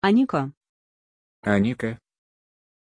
Aussprache von Anikka
pronunciation-anikka-ru.mp3